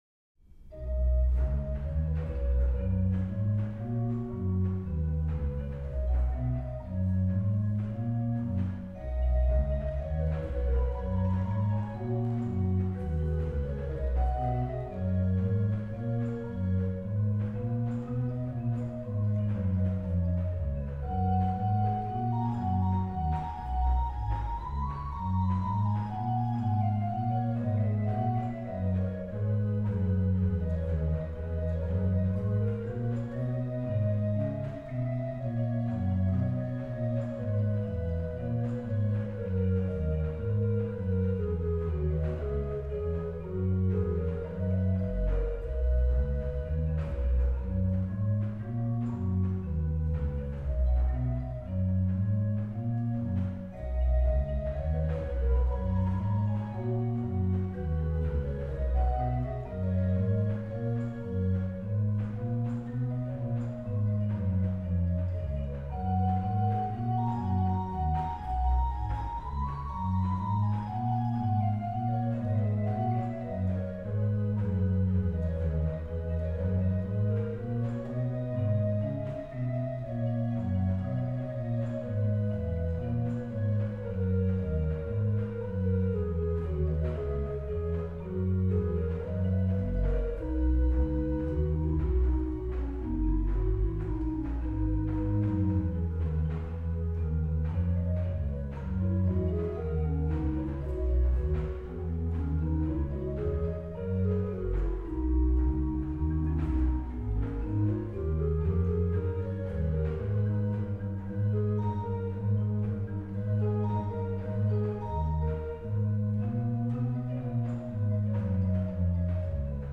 Registration   rh: MAN: Bor8
lh: POS: Fl 4 (8ve lower)
PED: Sub16, Bor8
Trem